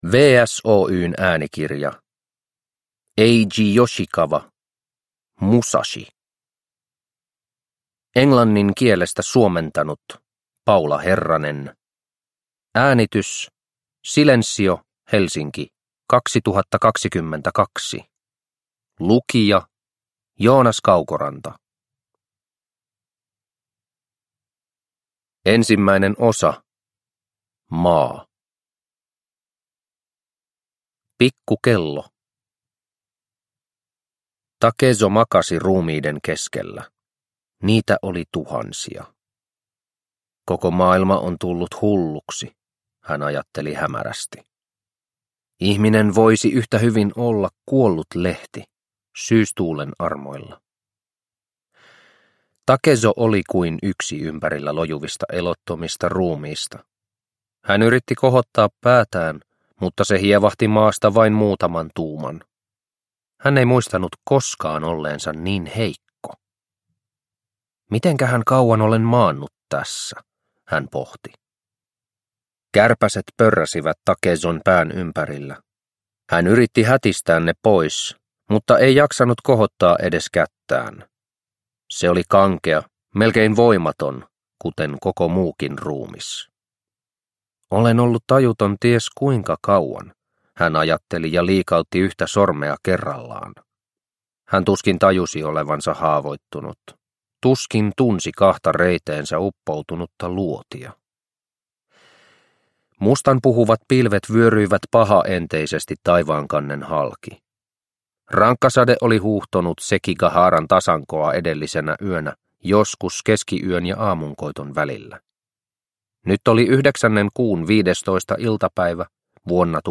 Musashi – Ljudbok – Laddas ner